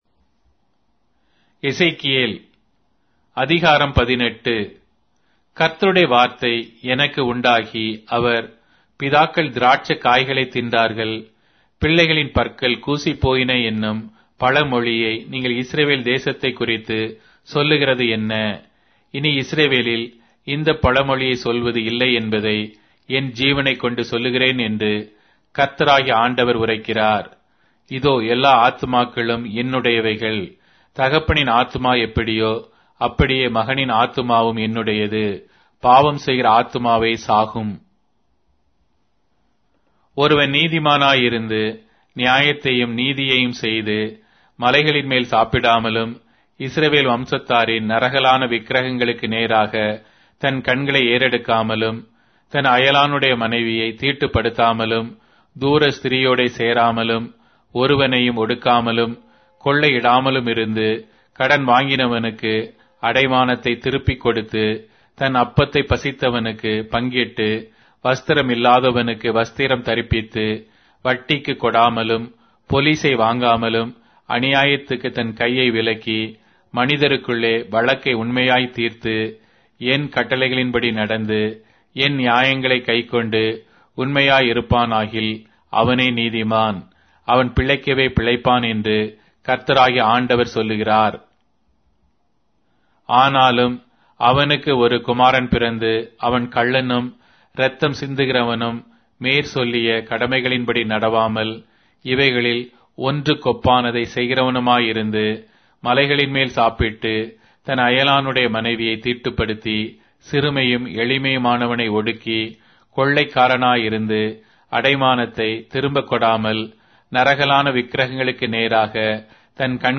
Tamil Audio Bible - Ezekiel 1 in Irvbn bible version